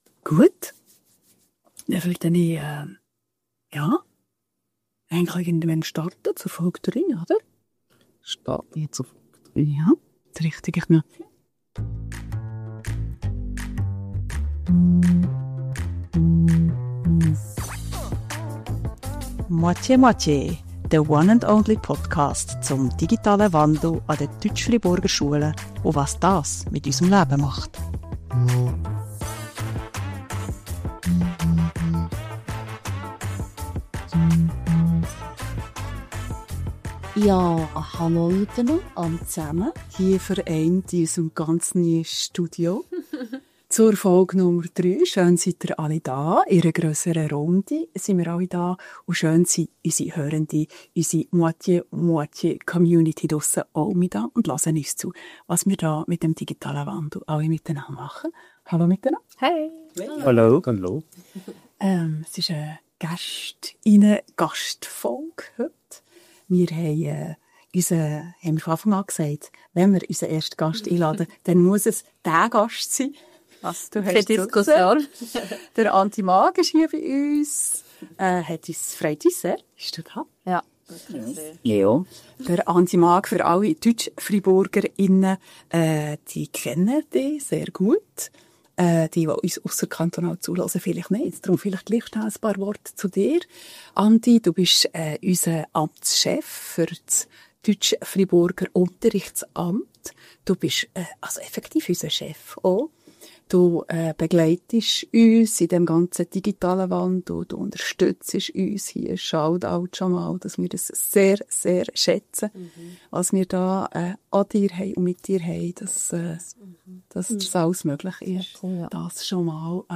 Beschreibung vor 6 Monaten In dieser Folge treffen wir Andreas Maag – Amtschef der Erziehungsdirektion des Kantons Freiburg – auf eine heitere Talkrunde zwischen Rasenmäher, Crèmeschnitten und KI-Prompts.
Wie klingt Bildung mit Noppenschaum im neuen Studio?